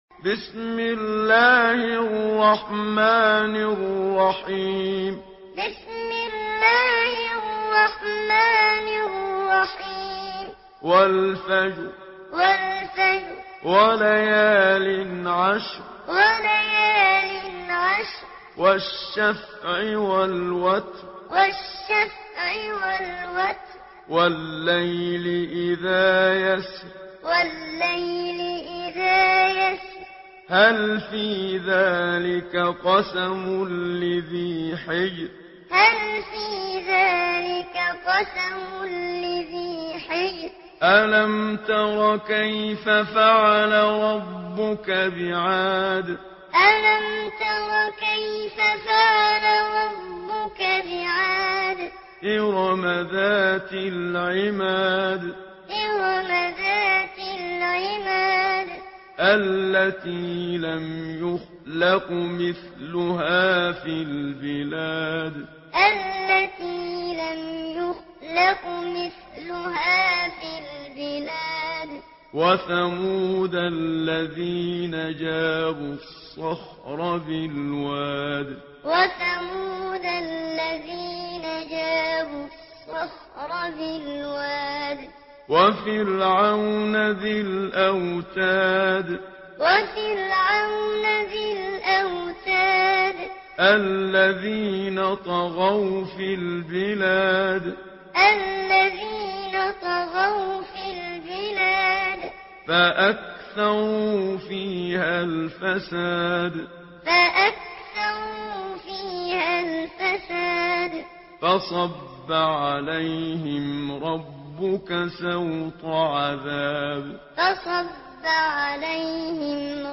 سورة الفجر MP3 بصوت محمد صديق المنشاوي معلم برواية حفص